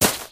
new_gravel2.ogg